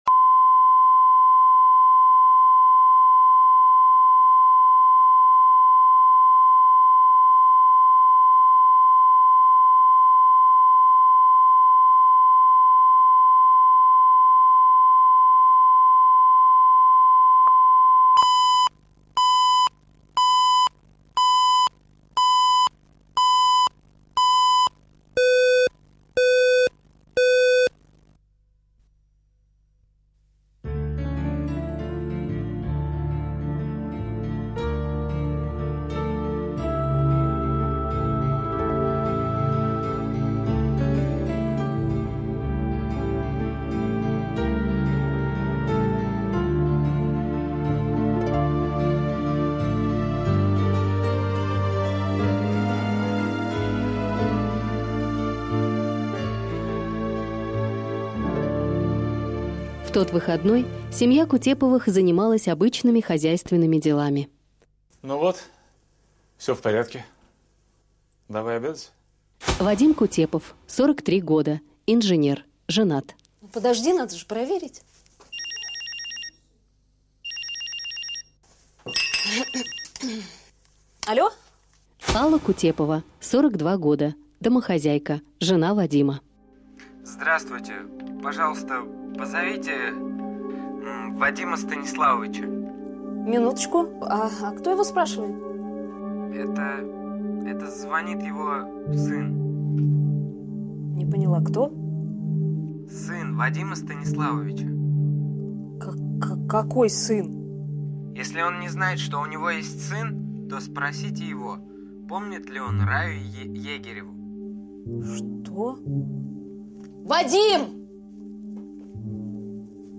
Аудиокнига Свин